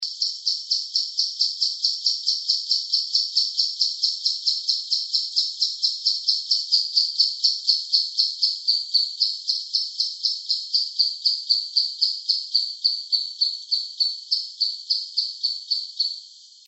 Arapasú (Dendrocincla turdina)
Nombre en inglés: Plain-winged Woodcreeper
Fase de la vida: Adulto
Localidad o área protegida: Reserva Privada y Ecolodge Surucuá
Condición: Silvestre
Certeza: Vocalización Grabada